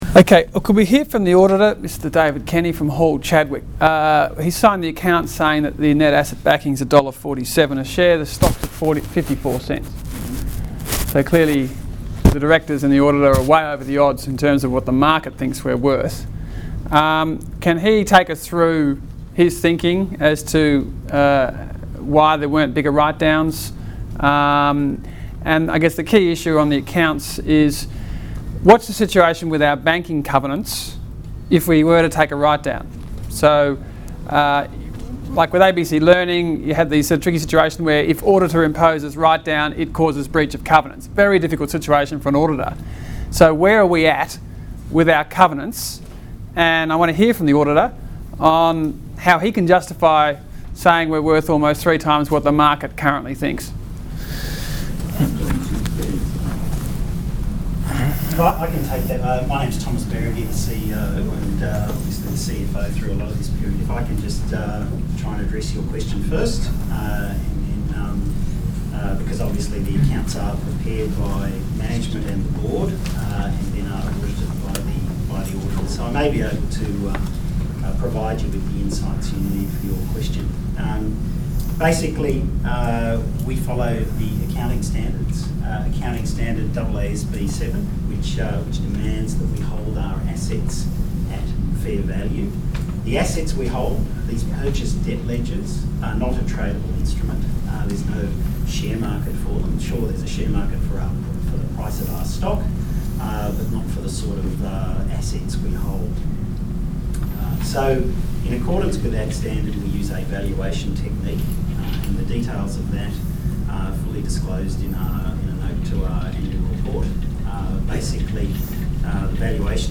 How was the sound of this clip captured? Below is a compilation of audio files from various AGMs over the years where auditors have been asked to comment on the accounts. Credit Corp AGM, November 10, 2008